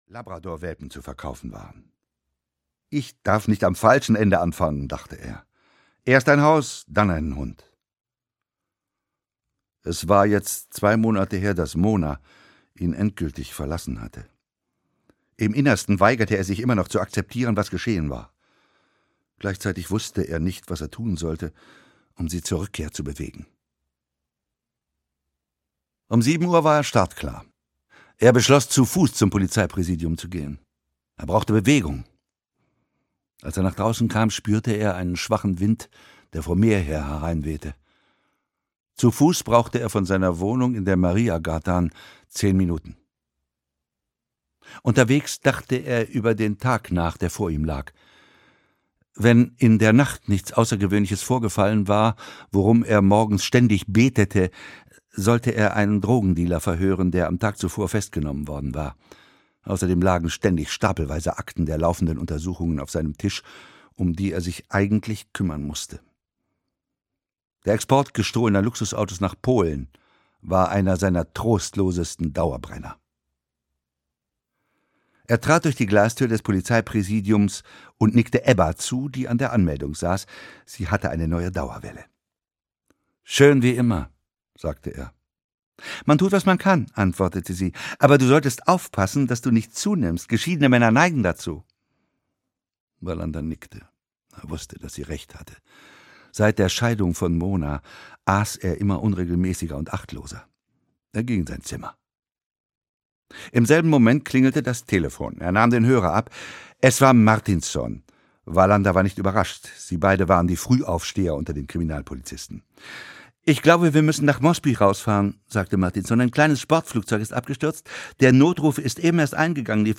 Die Pyramide (Ein Kurt-Wallander-Krimi) - Henning Mankell - Hörbuch